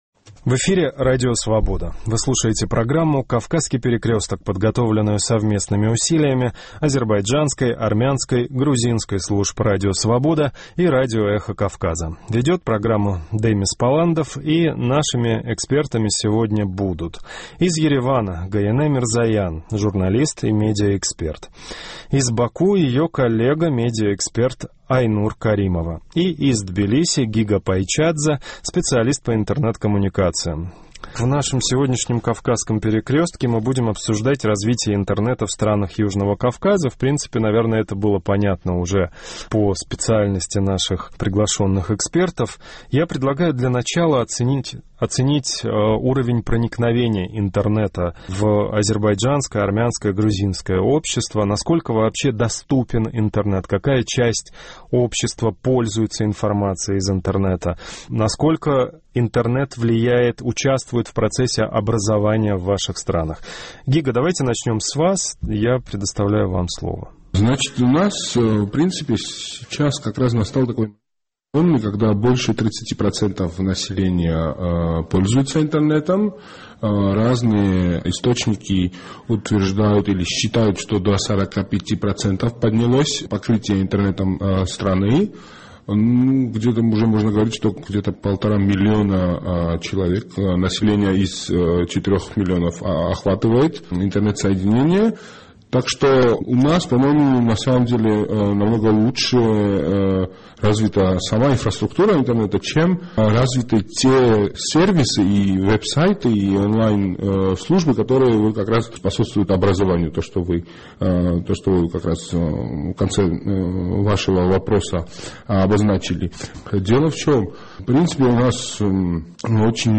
რადიო თავისუფლების სომხური, აზერბაიჯანული და ქართული რედაქციების ერთობლივი ყოველკვირეული რეგიონული პროგრამის დღევანდელი სტუმრები არიან: ერევნიდან